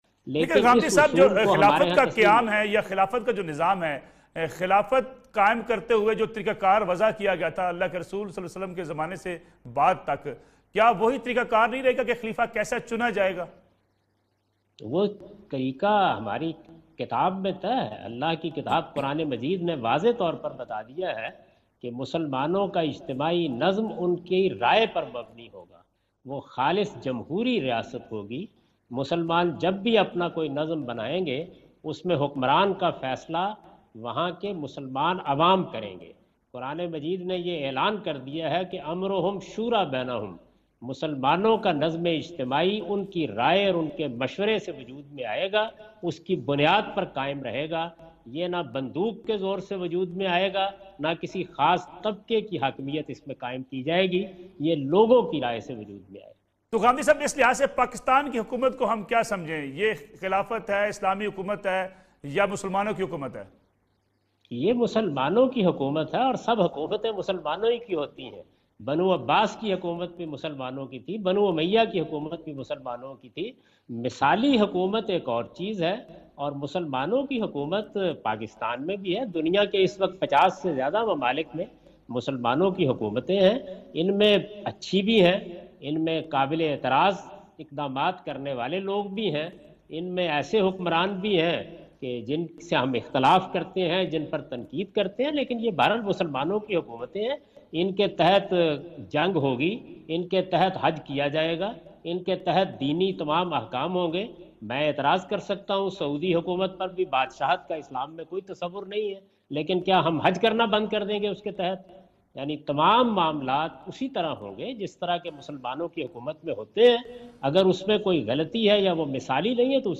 Category: TV Programs / Neo News /
In this program Javed Ahmad Ghamidi answer the question about "Establishment of Khilafat" on Neo News.